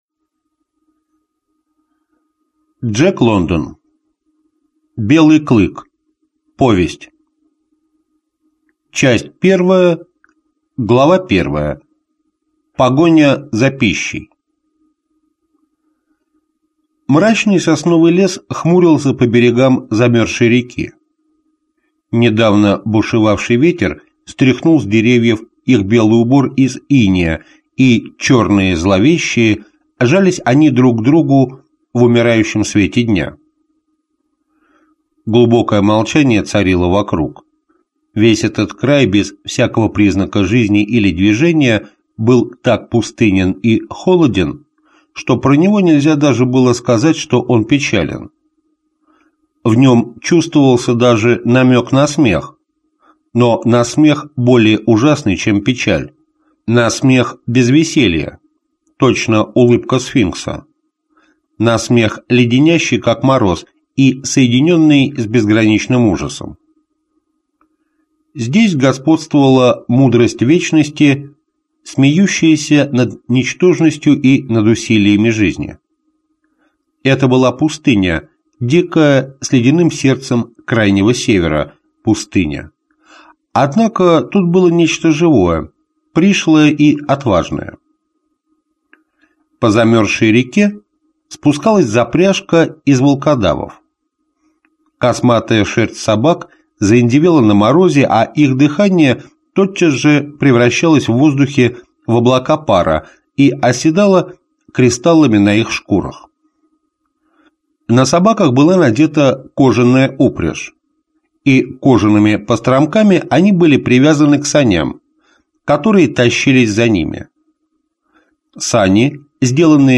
Аудиокнига Белый Клык. Зов предков | Библиотека аудиокниг